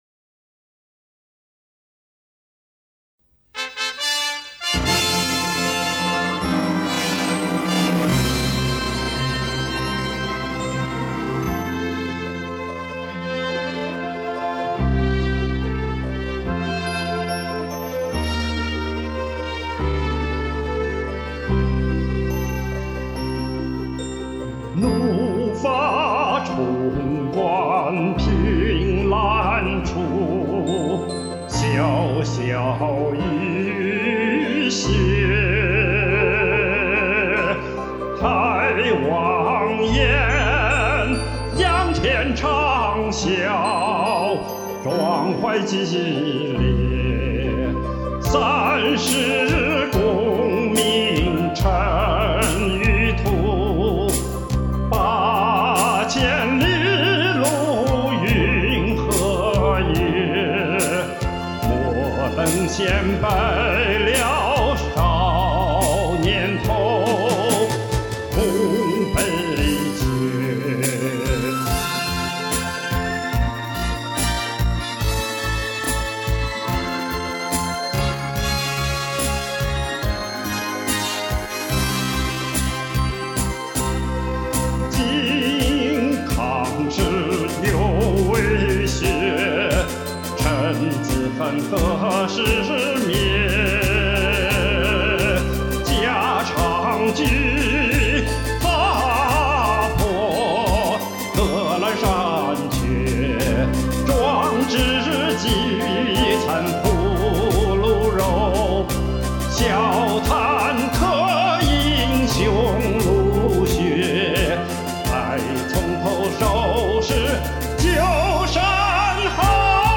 忍不住跟在後麵也吼上這段流傳已久的老歌【滿江紅】作和.